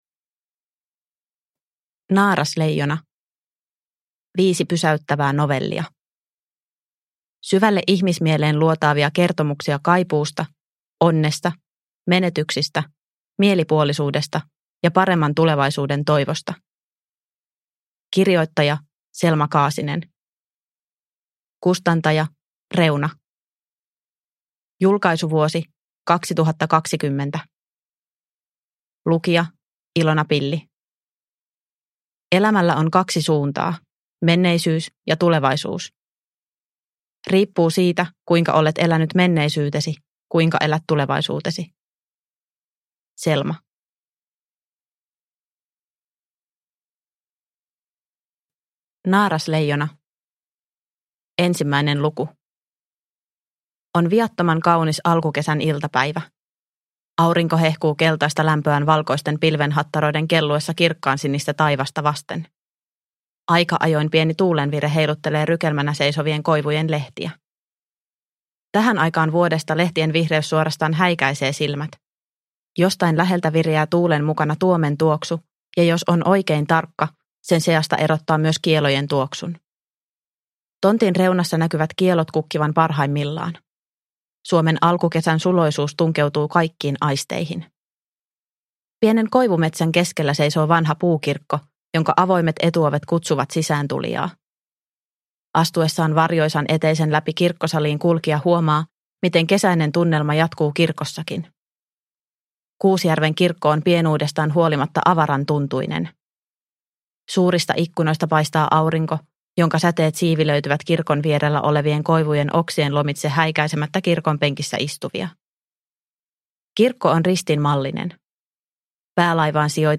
Naarasleijona – Ljudbok – Laddas ner